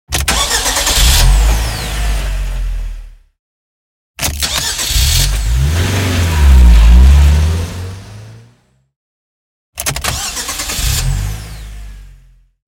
جلوه های صوتی
دانلود صدای ماشین 24 از ساعد نیوز با لینک مستقیم و کیفیت بالا